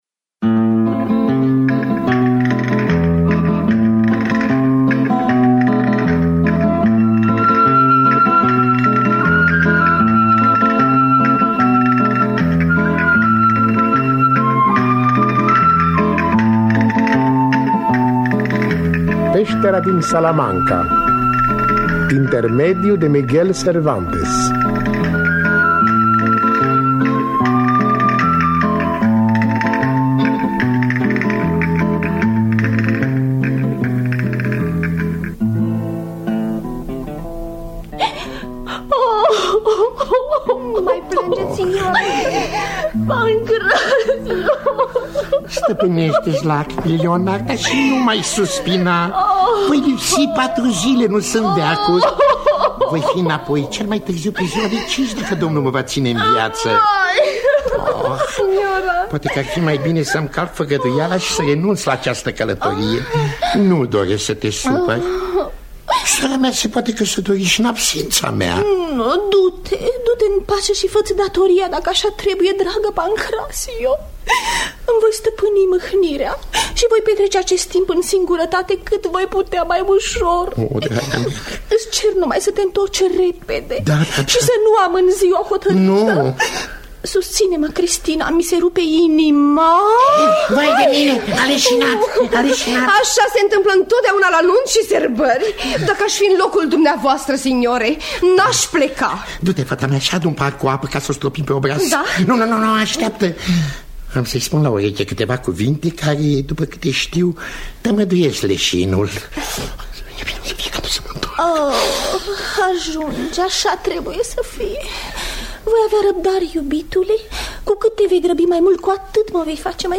Peştera din Salamanca de Miguel de Cervantes – Teatru Radiofonic Online